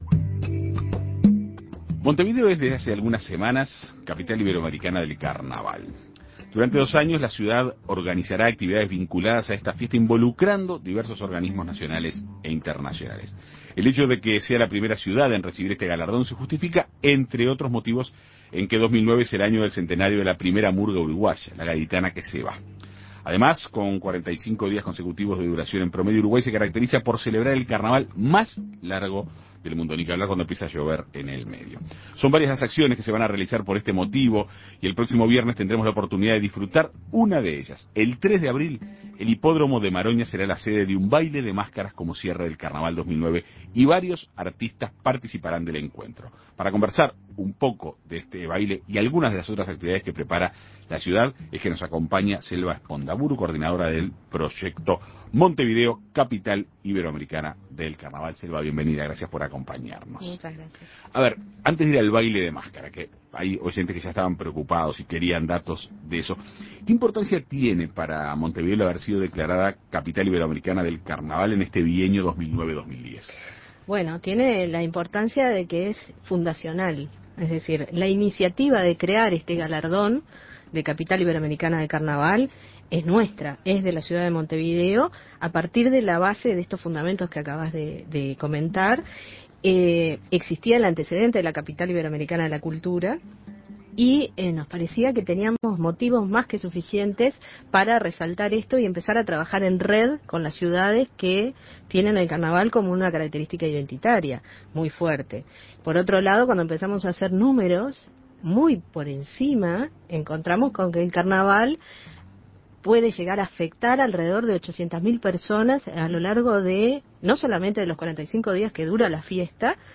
La primera celebración tendrá lugar el 3 de abril con un baile de máscaras en el Hipódromo de Maroñas. En Perspectiva Segunda Mañana dialogó